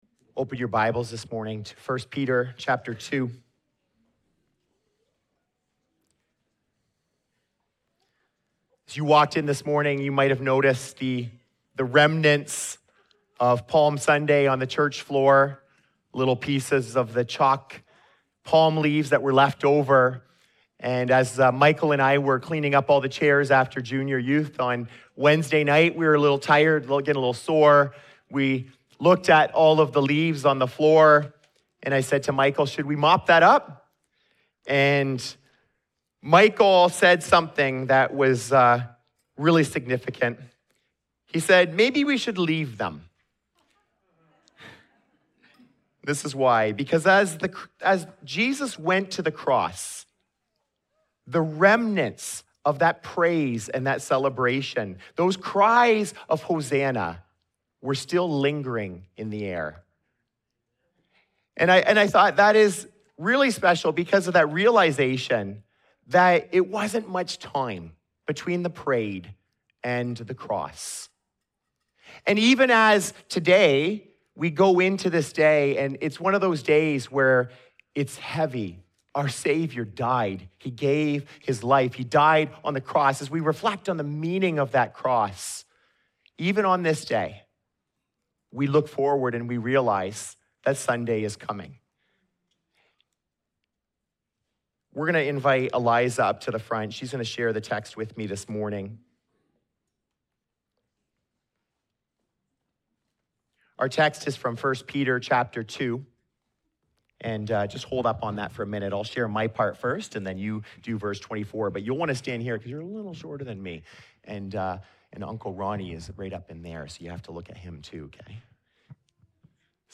This Good Friday message centres on the life-changing truth of the cross in 1 Peter 2 Vs 22–25. Jesus didn’t just die—He took our place, bore our sins, and broke sin’s power over us.